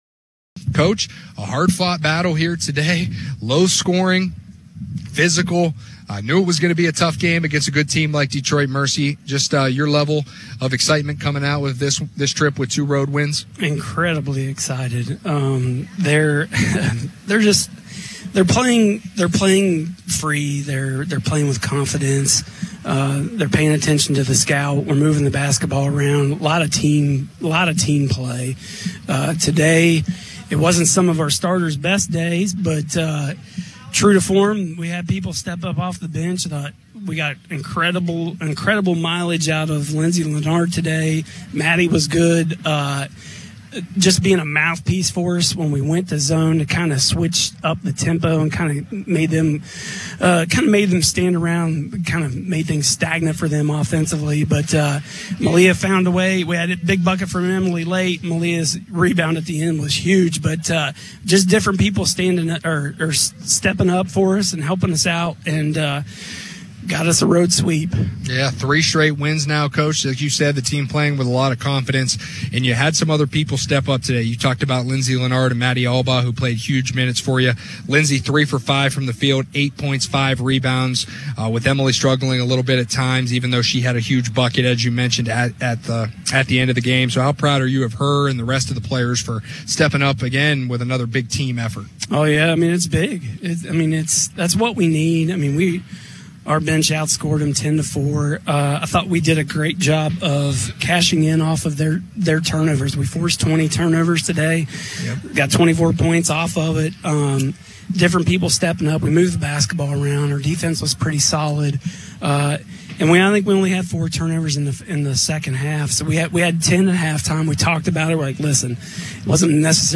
WBB: Postgame Interview